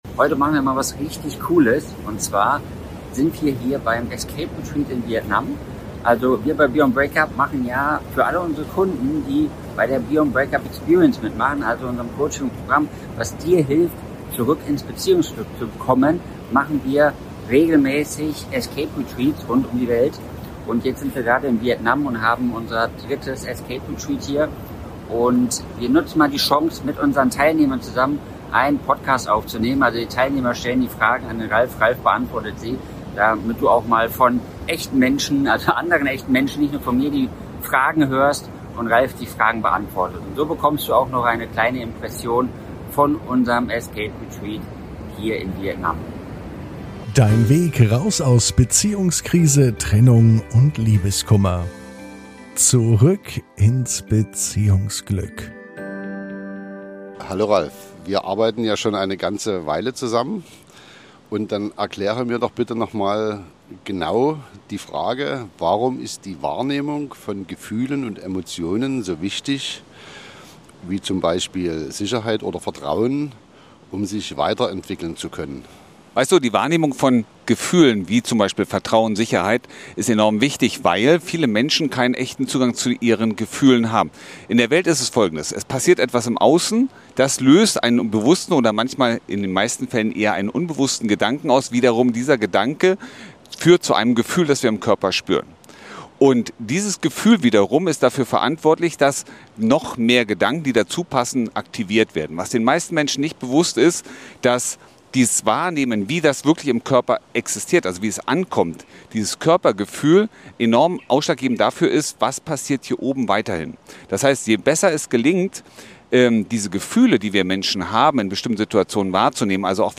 In dieser ganz besonderen Folge von Zurück ins Beziehungsglück gibt es kein klassisches Interview-Format. Wir lassen die Menschen sprechen, die beim letzten Beyond Breakup Escape Retreat dabei waren.